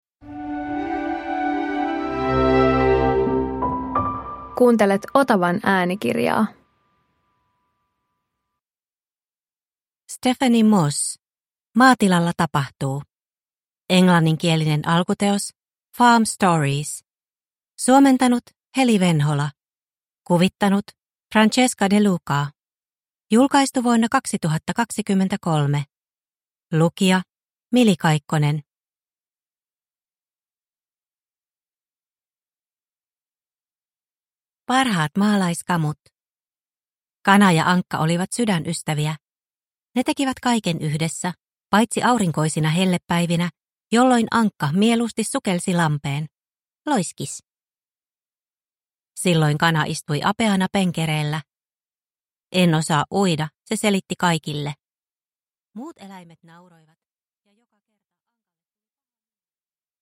Maatilalla tapahtuu – Ljudbok